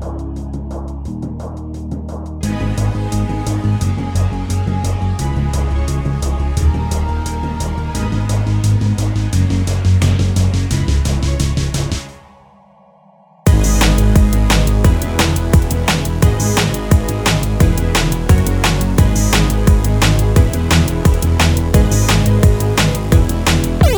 End Cut Down Pop (2010s) 3:23 Buy £1.50